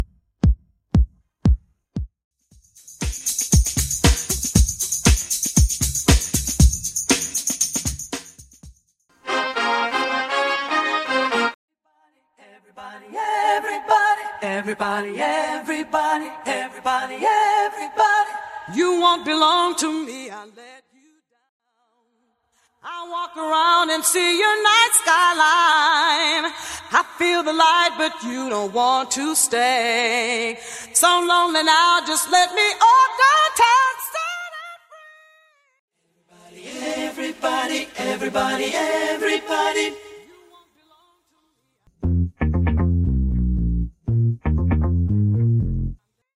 Backing Vocals Stem
Keys, Trumpets , Pads & Synths Stem
Leading Vocals Stem
Percussion & Drums Stem
A classic from the 80s.